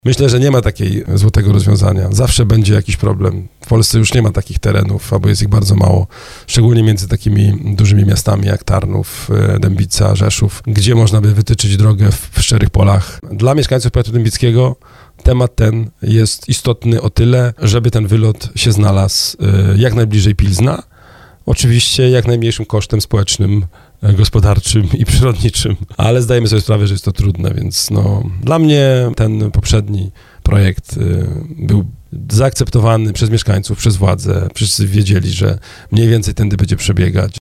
Dla nas najkorzystniejszą opcją jest ta ze zjazdem z autostrady A4 w Pilźnie – mówił na antenie RDN Małopolska Starosta Powiatu Dębickiego. Piotr Chęciek w porannej rozmowie Słowo za Słowo odniósł się do tematu budowy wschodniej obwodnicy Tarnowa, która wciąż wywołuje burzliwą dyskusję nad tym, gdzie powinna przebiegać.